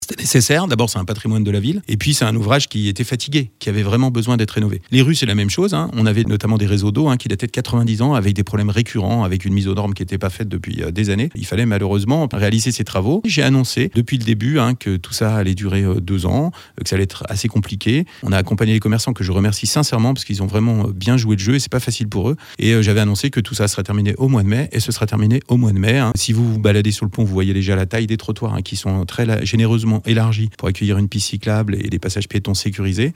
Ces travaux étaient évidemment nécessaires comme le rappelle Stéphane Valli le maire de Bonneville